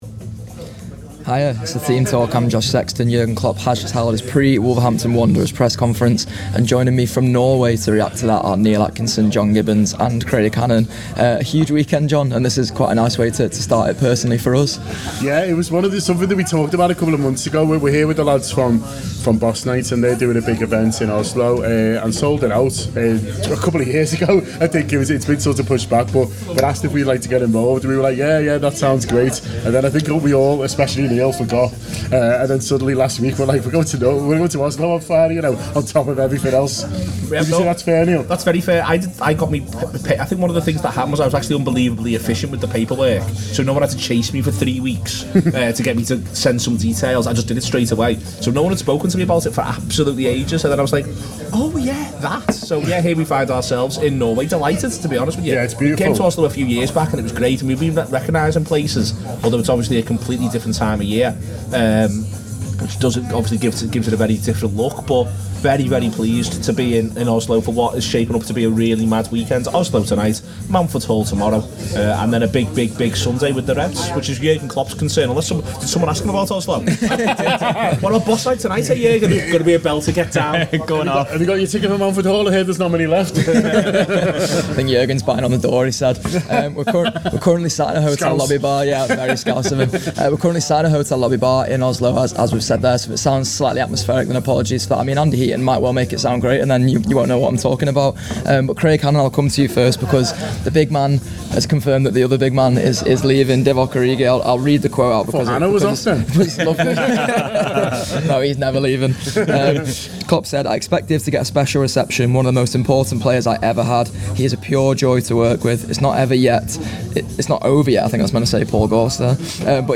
Below is a clip from the show – subscribe for more on the Liverpool v Wolves press conference…